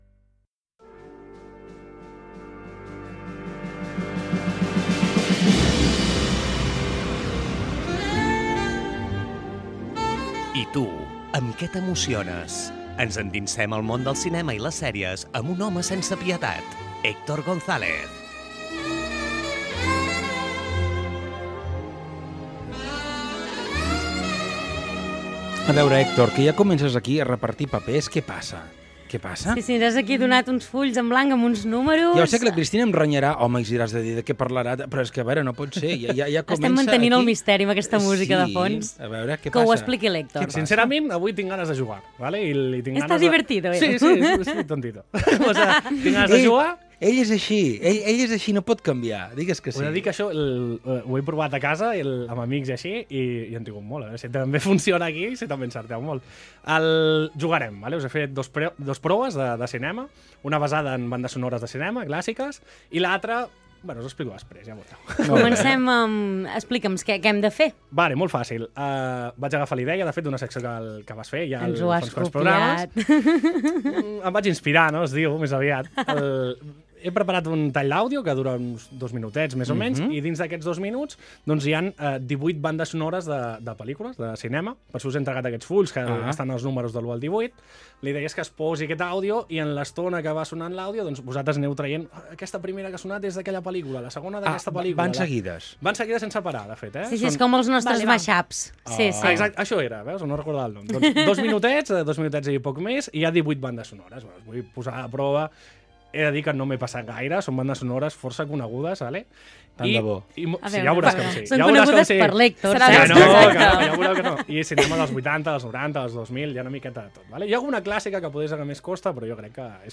Sexta colaboración con el programa “La Tropa” de la emisora de radio “Fem Girona”.
Concurso: Mashup de BSO y frases de Clint Eastwood Para el que no lo conozca, un Mashup de BSO es un corte de audio donde en poco tiempo, se incluyen muchas bandas sonoras míticas del cine.
He cogido seis frases de sus películas y las he cortado, haciendo que mis compañeros tuvieran que adivinar como continuaba. Risas y premios en esta sección de cine de «La Tropa», en radio «Fem Girona».